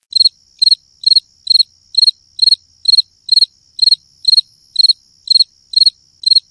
PLAY Cri Cri Grillo
grillo-cri-cri-f.mp3